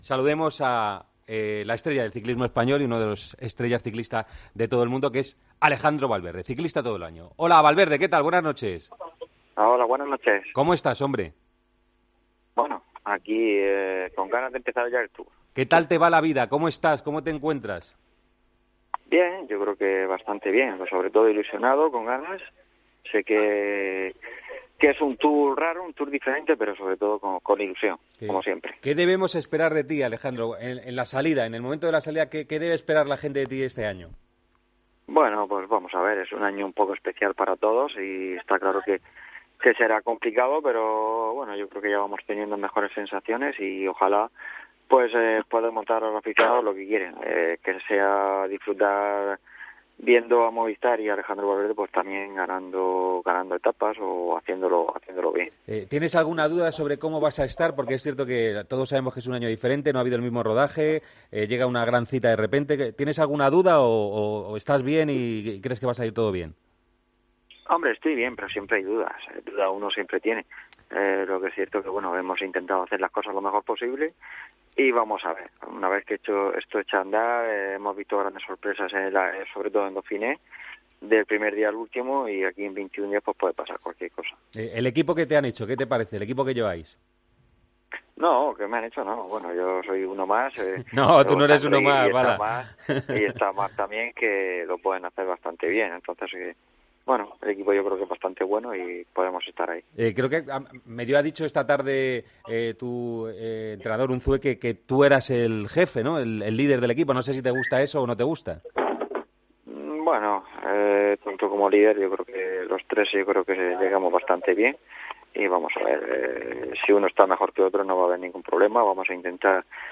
Alejandro Valverde ha visitado El Partidazo de COPE para analizar su participación en el Tour de Francia 2020 que arranca este sábado